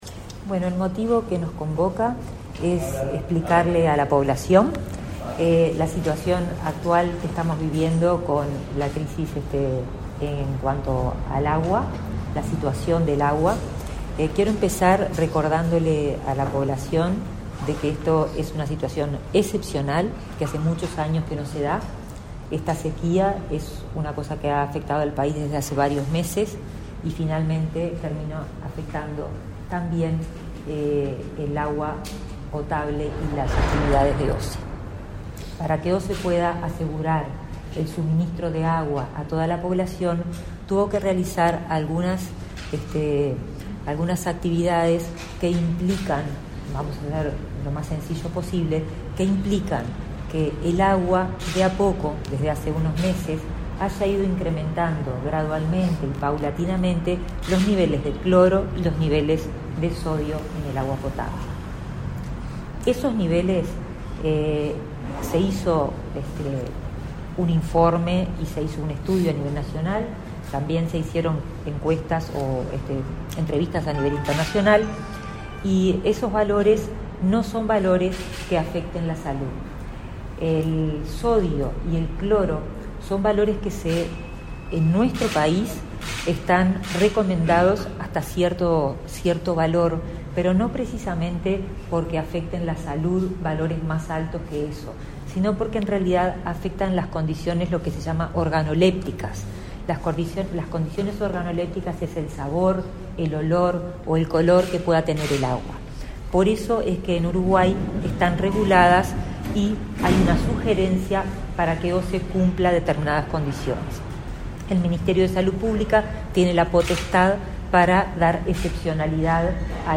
Palabras de la ministra de Salud Pública, Karina Rando
Palabras de la ministra de Salud Pública, Karina Rando 04/05/2023 Compartir Facebook Twitter Copiar enlace WhatsApp LinkedIn La ministra de Salud Pública, Karina Rando, realizó una conferencia de prensa, este 4 de mayo, para informar acerca de la situación ante el aumento de salinidad del agua potable distribuida por OSE.